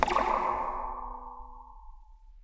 zap3_v1.wav